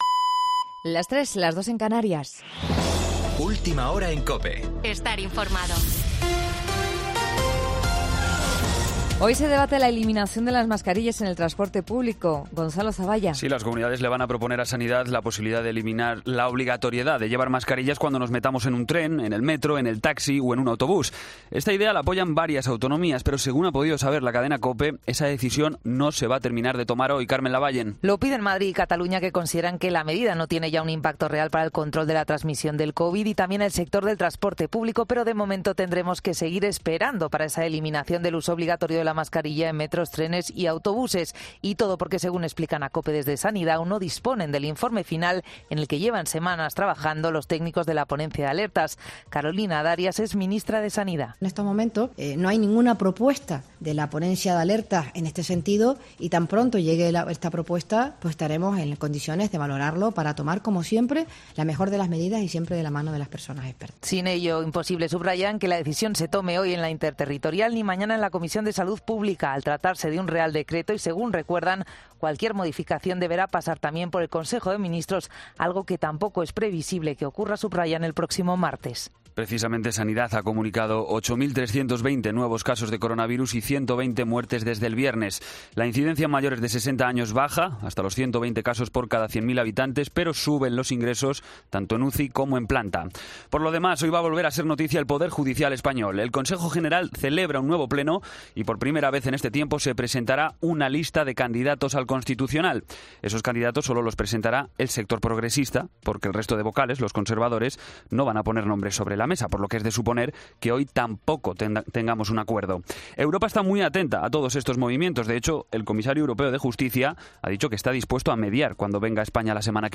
Boletín de noticias COPE del 21 de septiembre a las 03:00 hora